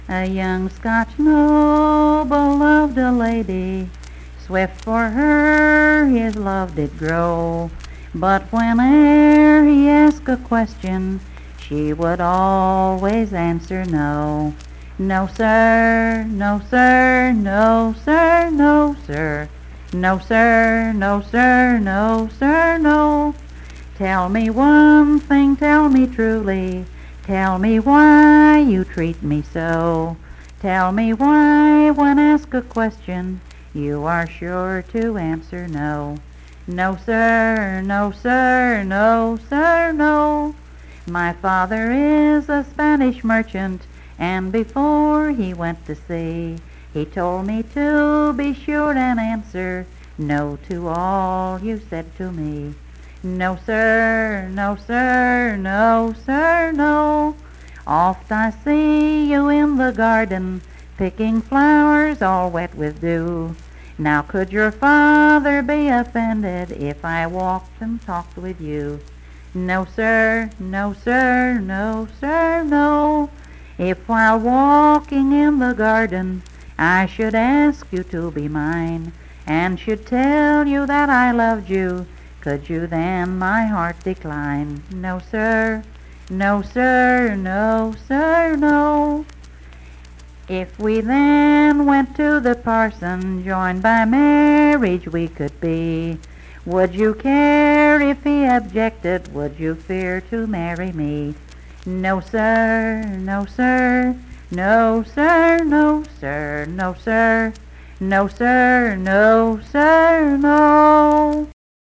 Unaccompanied vocal music performance
Verse-refrain 6(6w/R).
Dance, Game, and Party Songs
Voice (sung)